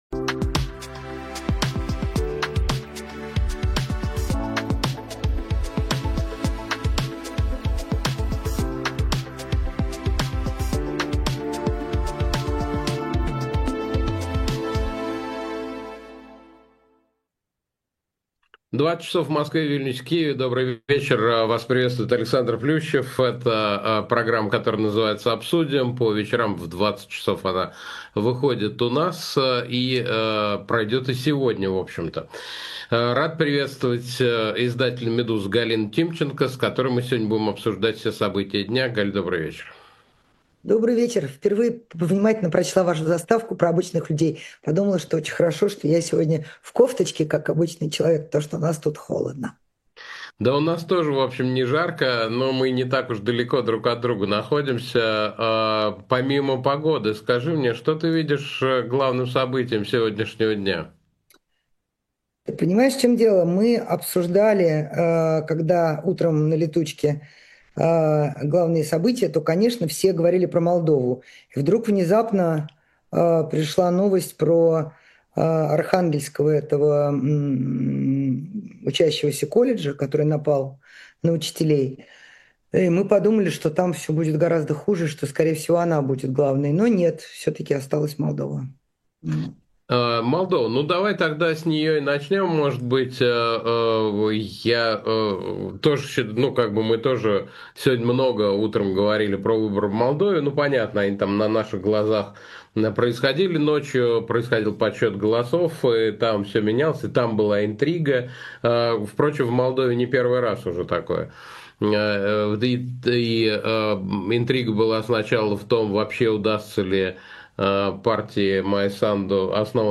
Эфир ведёт Александр Плющев.